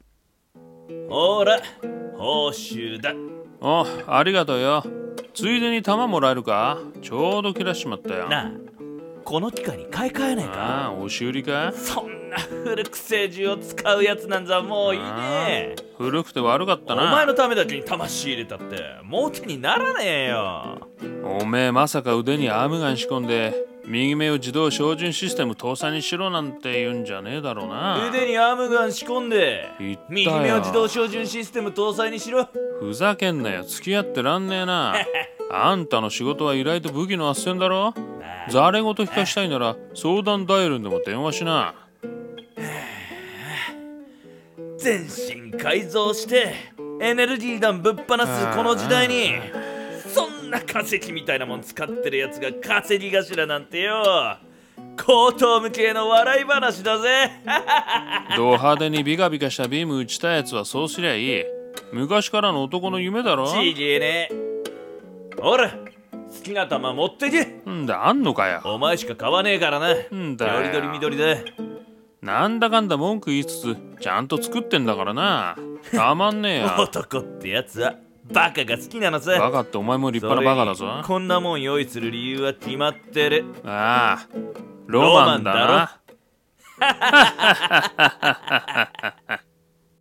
二人声劇】それがロマンだろ